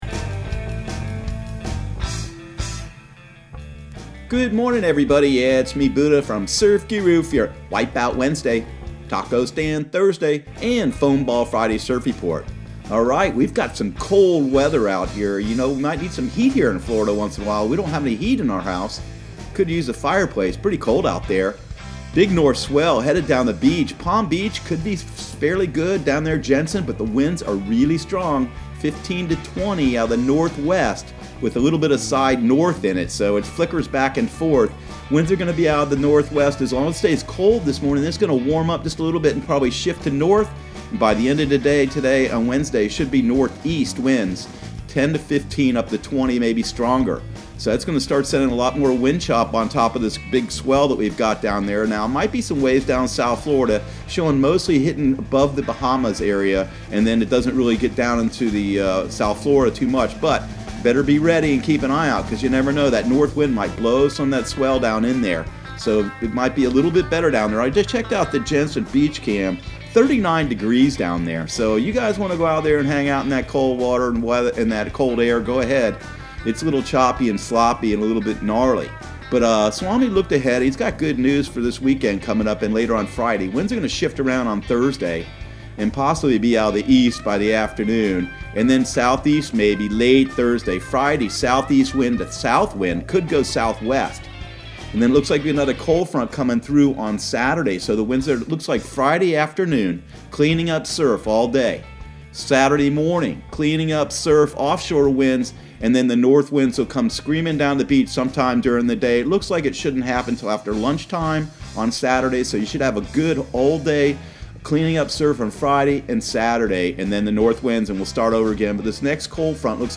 Surf Guru Surf Report and Forecast 01/22/2020 Audio surf report and surf forecast on January 22 for Central Florida and the Southeast.